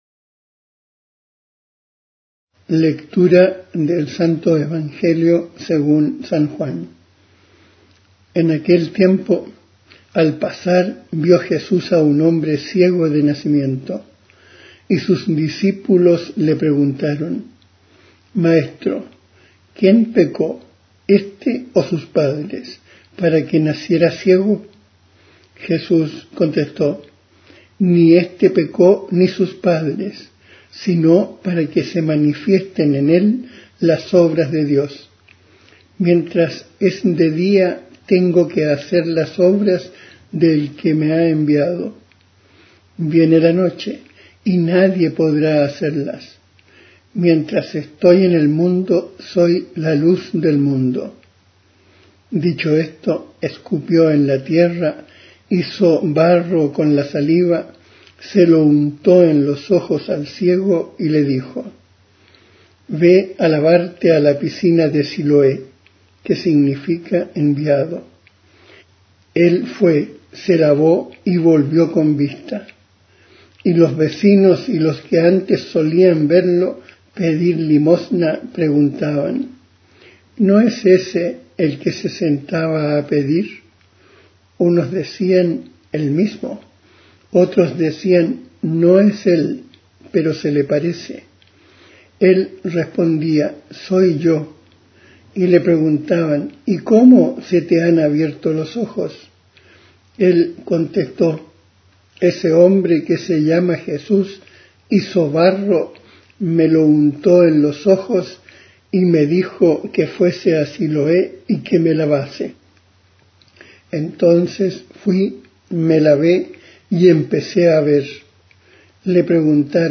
Evangelio en audio.
Musica di sottofondo
J.S.Bach. Matthaeus Passion. Erbarme Dich mein Gott. Diritti Creative Commons